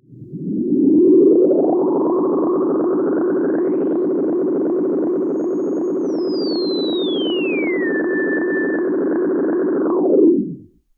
Filtered Feedback 06.wav